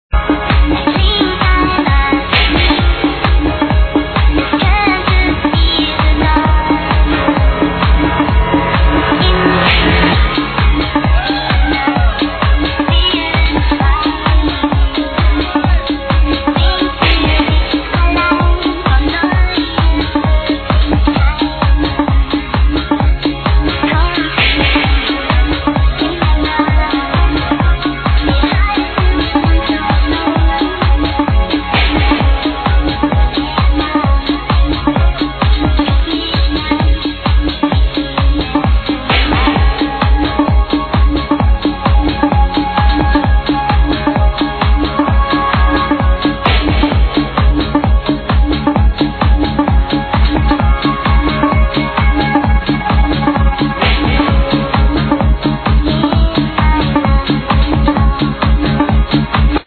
vocal track
It sounds like this sample is in the middle of a mix